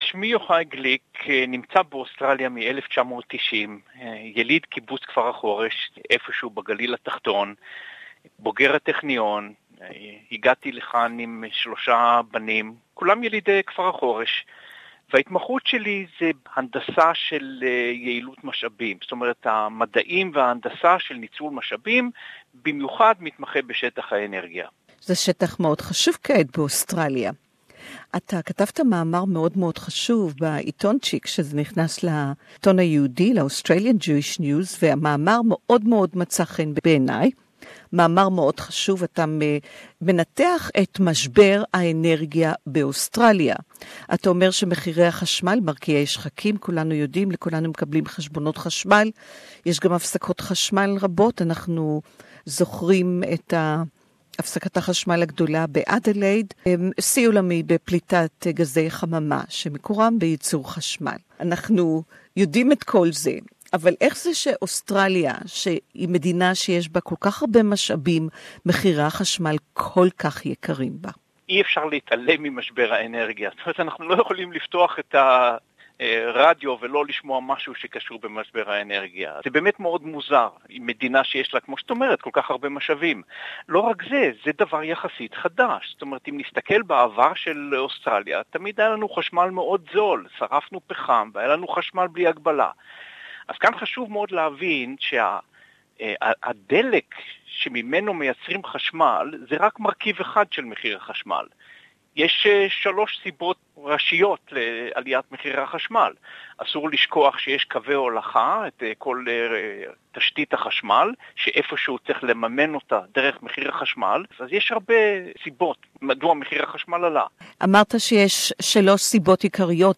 Interview in Hebrew